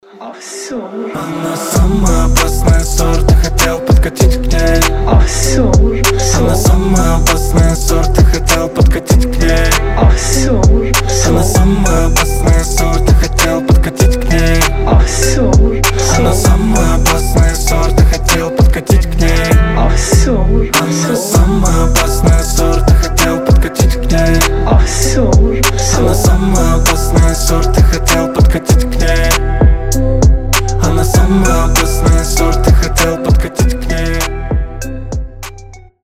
• Качество: 320, Stereo
мужской голос
басы
качающие